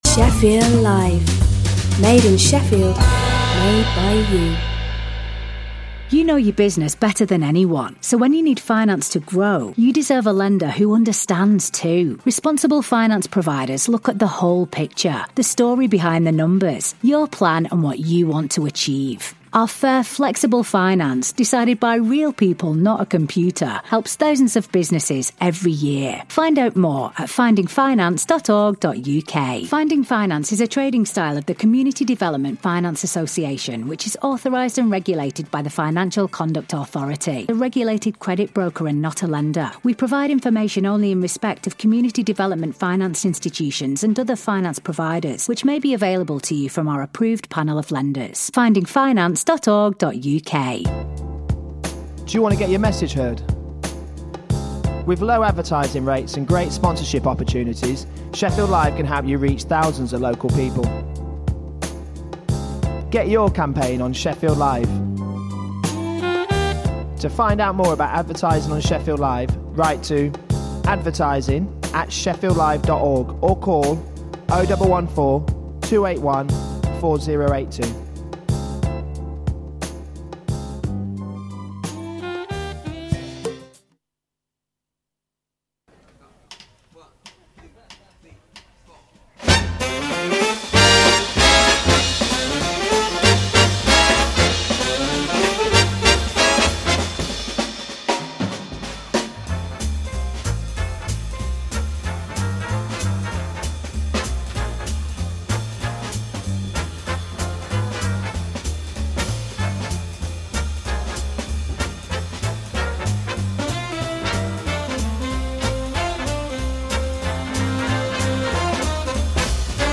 Film and theatre reviews plus swing classics.